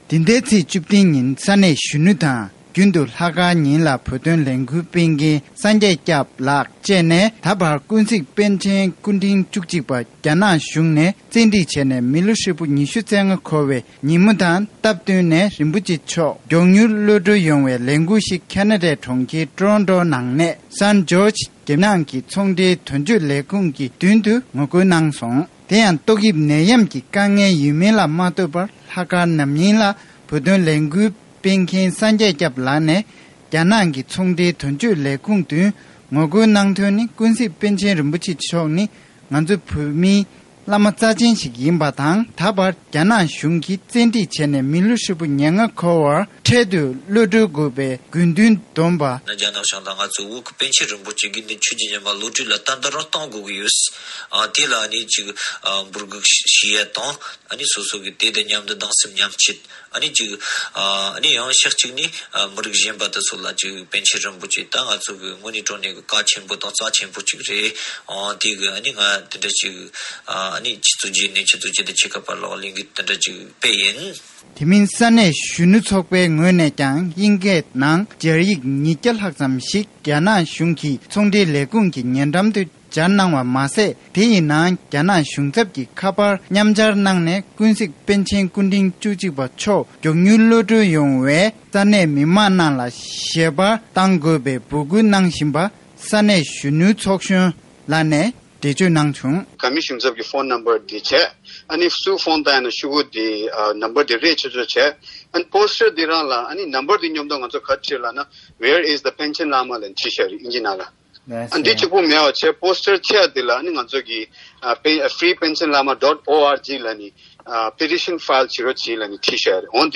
ཁེ་ན་ཌའི་ནང་པཎ་ཆེན་པོ་ཆེ་གློད་བཀྲོལ་ཆེད་ངོ་རྒོལ་སྤེལ་བ། སྒྲ་ལྡན་གསར་འགྱུར།